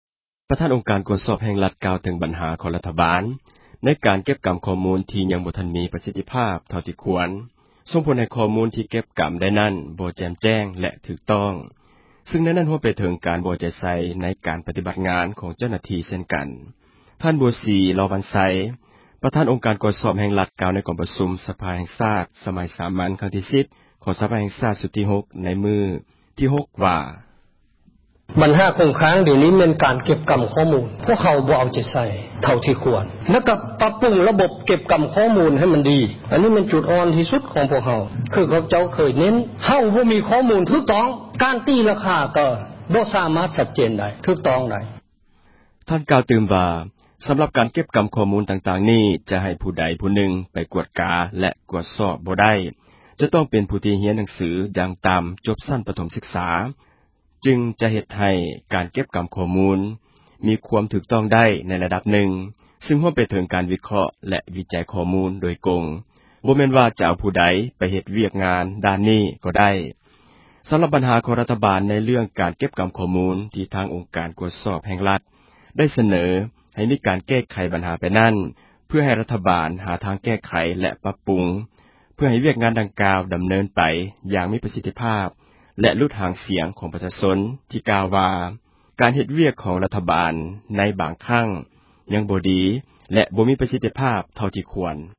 ປະທານ ອົງການ ກວດສອບແຫ່ງຣັຖ ກ່າວເຖິງບັນຫາ ຂອງ ຣັຖບານ ໃນການ ເກັບກໍາຂໍ້ມູນ ທີ່ຍັງບໍ່ມີ ປະສິດທິພາບ ເທົ່າທີ່ຄວນ, ສົ່ງຜົນໃຫ້ຂໍ້ມູນ ທີ່ເກັບກໍາ ໄດ້ນັ້ນ ບໍ່ແຈ່ມແຈ້ງ ແລະ ຖືກຕ້ອງ, ຊຶ່ງໃນນັ້ນ ຮວມໄປເຖິງ ການບໍ່ເອົາໃຈໃສ່ ໃນການ ປະຕິບັດງານ ຂອງເຈົ້າໜ້າທີ່ ເຊັ່ນກັນ. ທ່ານ ບົວສີ ລໍວັນໄຊ ປະທານ ອົງການ ກວດສອບ ແຫ່ງຣັຖ ກ່າວໃນກອງປະຊຸມ ສະພາແຫ່ງຊາດ ສມັຍສາມັນ ຄັ້ງທີ 10 ຂອງ ສະພາແຫ່ງຊາດ ຊຸດທີ 6 ໃນມື້ທີ 6 ວ່າ: